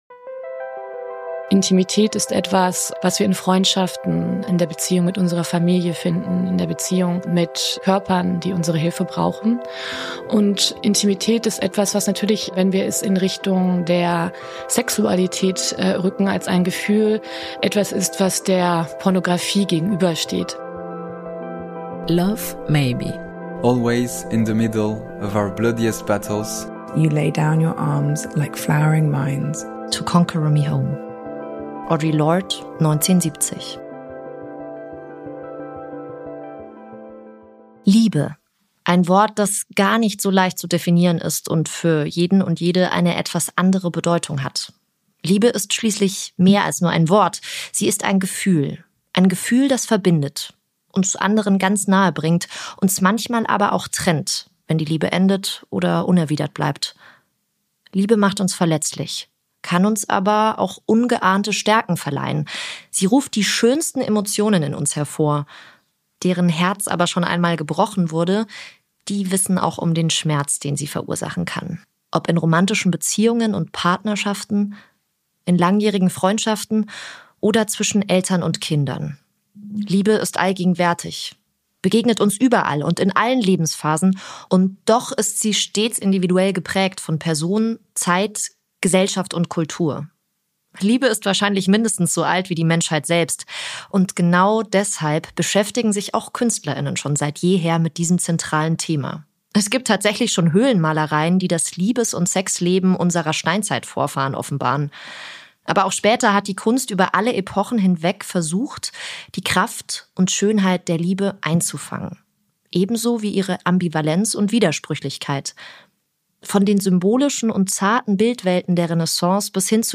Künstler Oliver Chanarin und Autorin Emilia Roig sprechen über Intimität jenseits idealisierter Körperbilder. In einer digitalen Welt, die uns permanent zur Selbstoptimierung drängt, versuchen sich die Künstler:innen von Perfektion abzuwenden - Und damit neue Perspektiven auf Intimität zu schaffen, die traditionelle Rollenvorstellungen herausfordern.